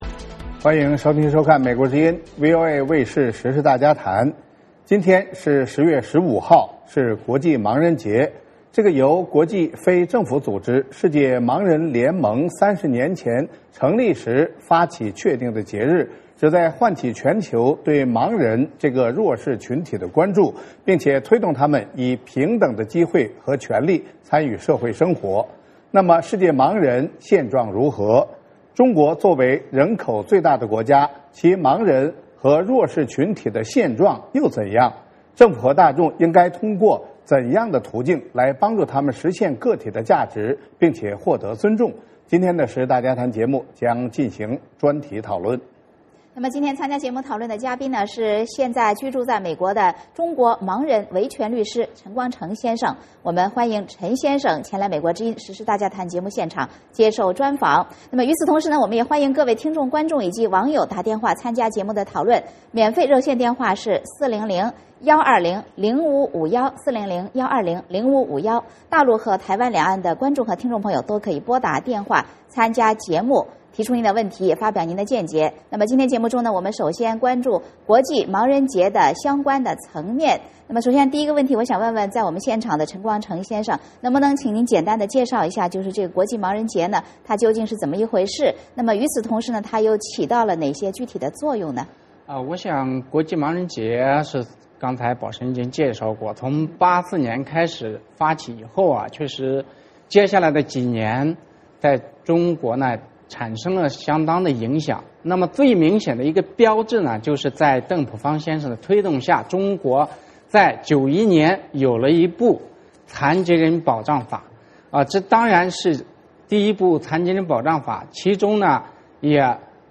时事大家谈：专访陈光诚之一：国际盲人日话弱势权益